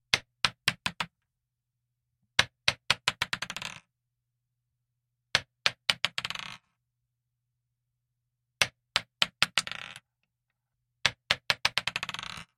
Звуки металлического шарика
Звук прыгающего металлического шарика по столу